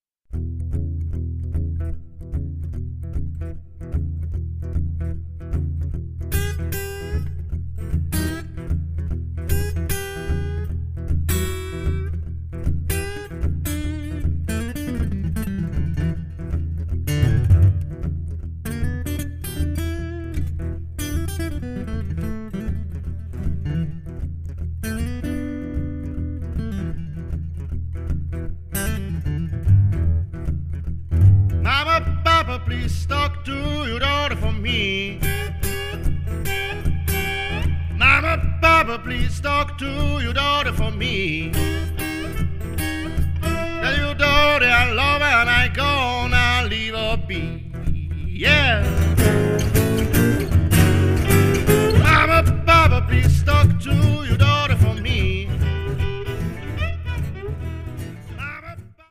violin
vocal, guitar, dobro, slide
guitar, harmonica, vocal